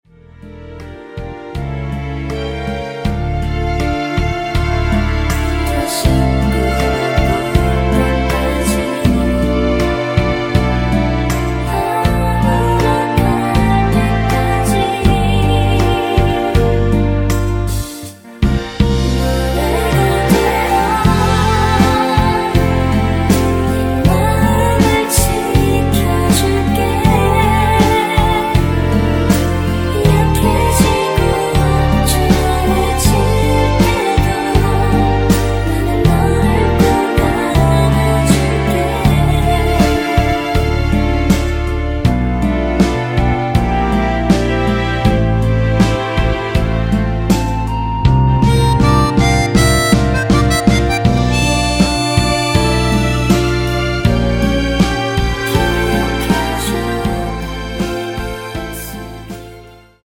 원키에서(+4)올린 멜로디와 코러스 포함된 MR입니다.(미리듣기 확인)
앞부분30초, 뒷부분30초씩 편집해서 올려 드리고 있습니다.